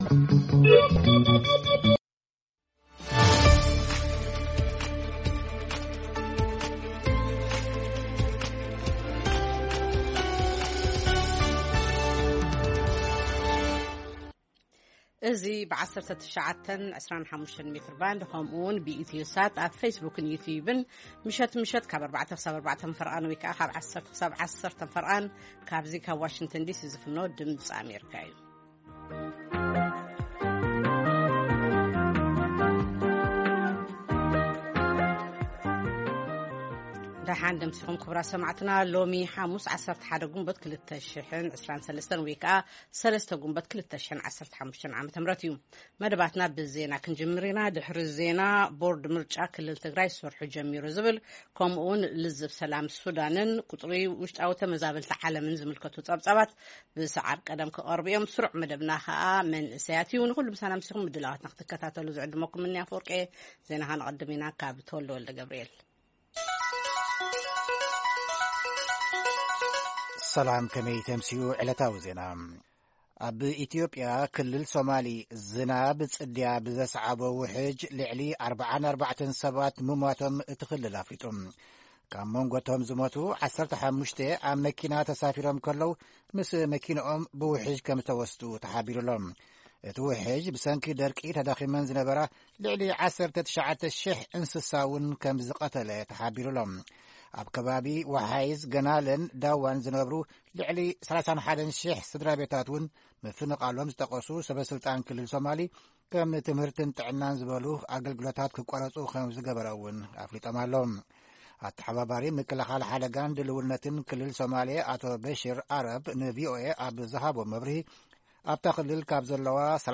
ፈነወ ድምጺ ኣመሪካ ቋንቋ ትግርኛ 11 ግንቦት 2023 ዜና (ኣብ ክልል ሶማል ኢትዮጵያ ዘጋጠመ ምዕልቕላቕ ዉሕጅ፡ ምብጻሕ ቦርድ ምርጫ ኢትዮጵያ ናብ ክልል ትግራይ፡ ኣመሪካ ኣብ ምቁራጽ ተኹሲ ሱዳን ዘለዋ ተስፋን ካል ኦትን) መደብ መንእሰያትን የጠቓልል።